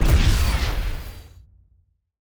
Sci-Fi Effects
weapon_blaster_002.wav